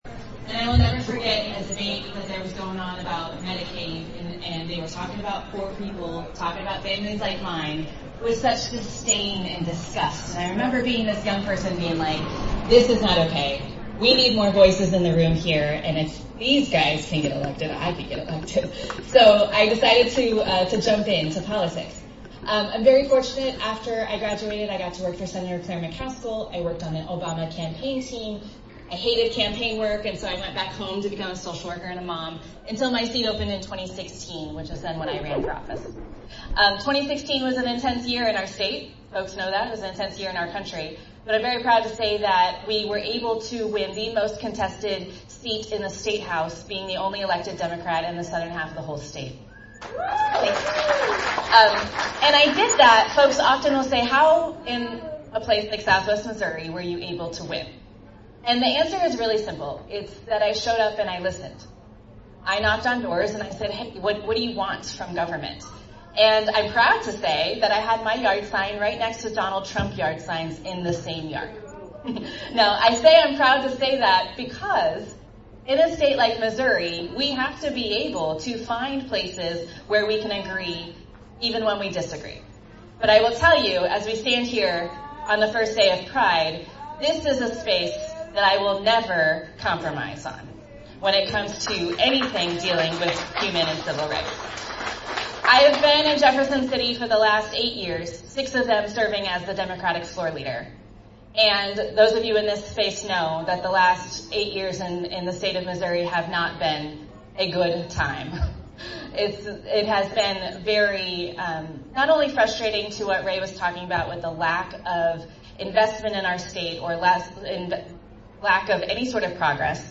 Several statewide and local Democratic Party candidates attended and spoke at the event.
House Minority Leader Crystal Quade (D), a candidate for Governor, attended, spoke to the crowd, and visited one-on-one with attendees.